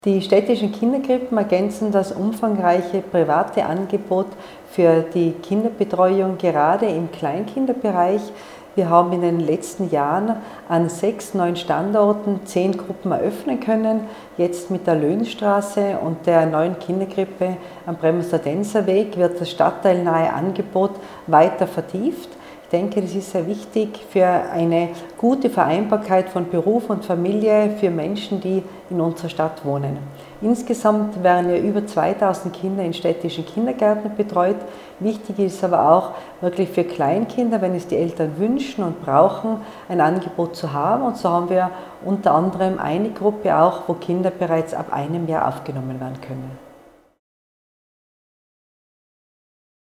OT von Bürgermeisterin Christine Oppitz-Plörer